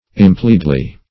impliedly - definition of impliedly - synonyms, pronunciation, spelling from Free Dictionary
impliedly - definition of impliedly - synonyms, pronunciation, spelling from Free Dictionary Search Result for " impliedly" : The Collaborative International Dictionary of English v.0.48: Impliedly \Im*pli"ed*ly\, adv. By implication or inference.